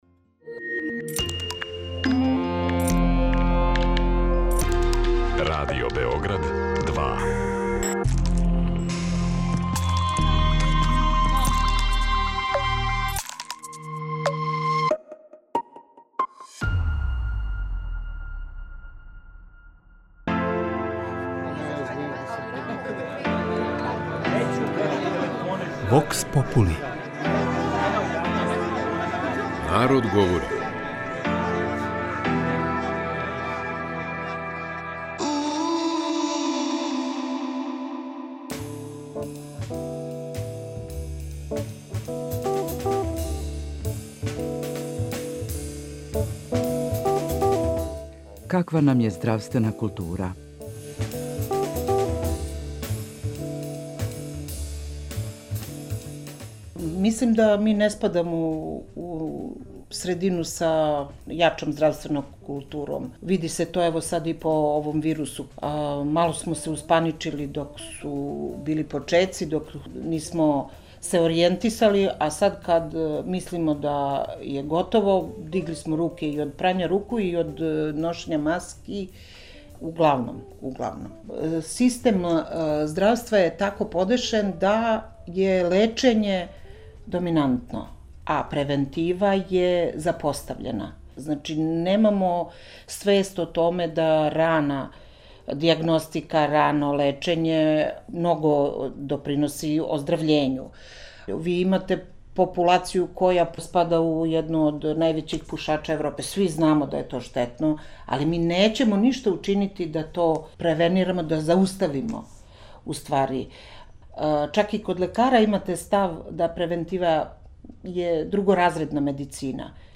„Вокс попули - Народ говори“ је емисија у којој ћемо слушати „обичан свет“, кроз кратке монологе, анкете и говорне сегменте у којима ће случајно одабрани, занимљиви саговорници одговарати на питања